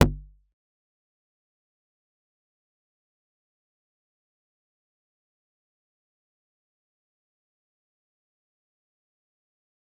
G_Kalimba-C1-pp.wav